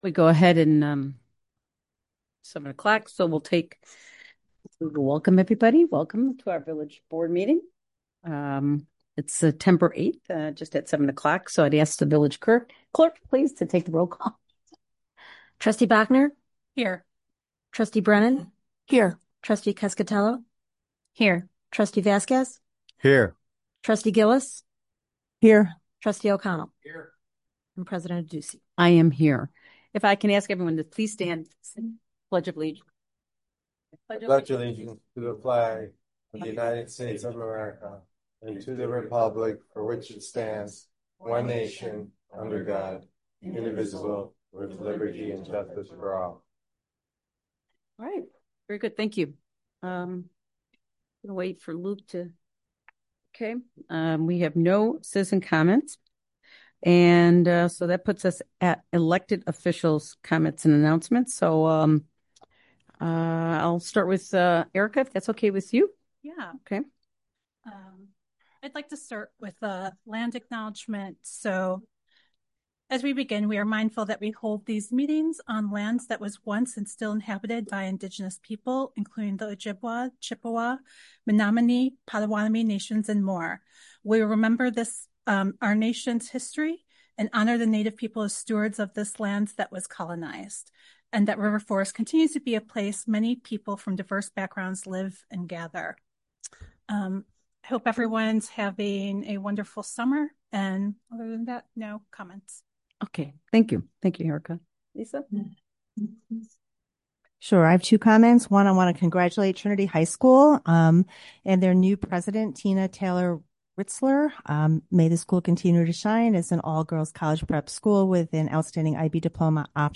Village Board of Trustees Meeting